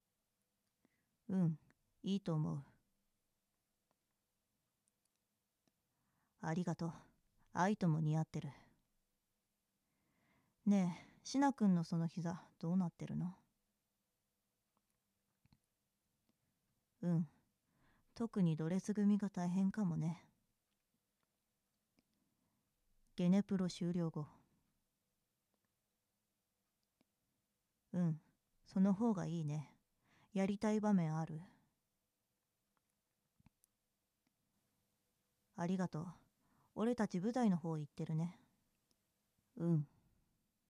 声劇🔮